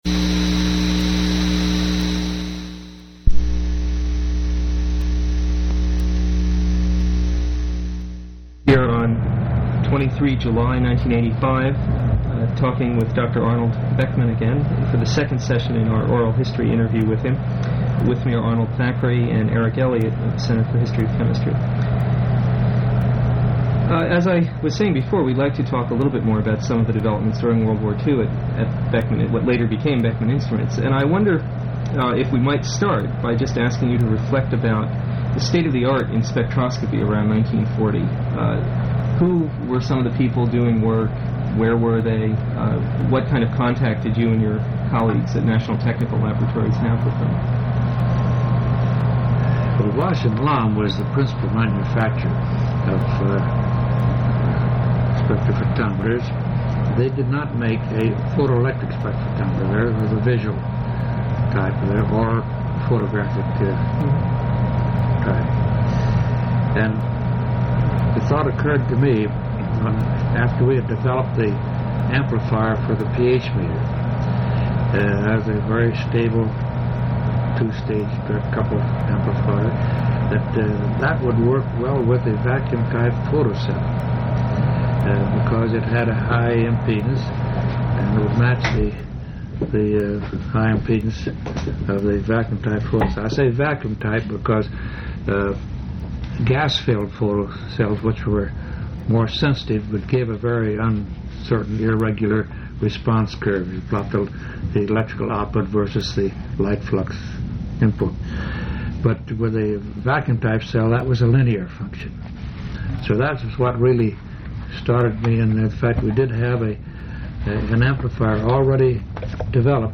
Oral history interview with Arnold O. Beckman